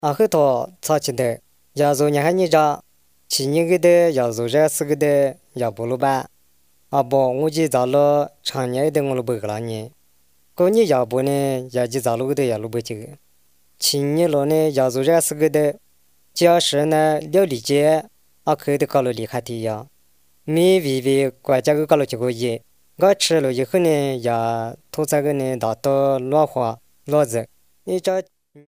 Most use a storytelling approach. These are recorded by mother-tongue speakers
Bible Stories, Discipleship, Music